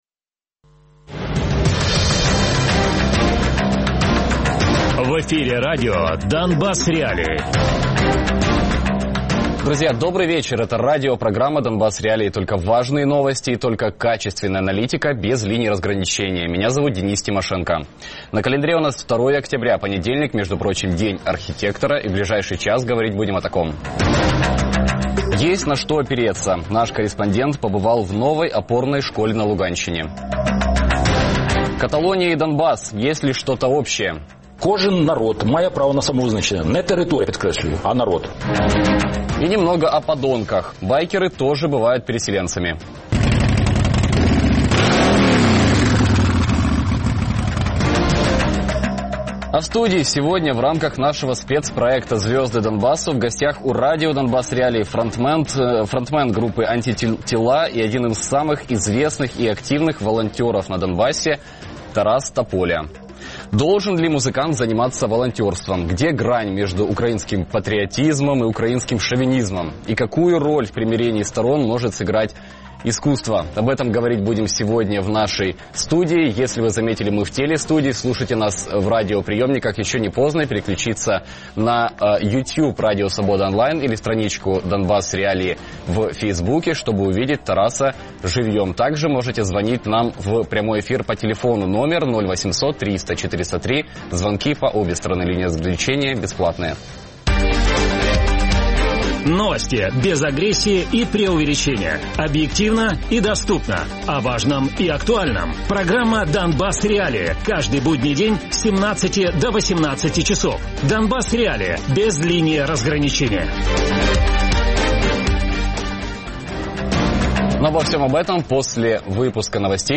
Гість: Тарас Тополя - 29-річний фронтмен групи «Антитіла», волонтер, учасник проекту «Зірки – Донбасу». Радіопрограма «Донбас.Реалії» - у будні з 17:00 до 18:00. Без агресії і перебільшення. 60 хвилин про найважливіше для Донецької і Луганської областей.